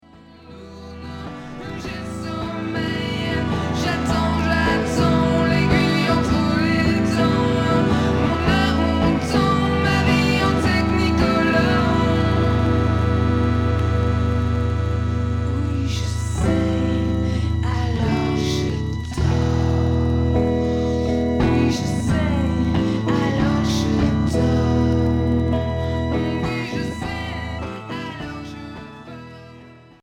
Pop rock Unique 45t retour à l'accueil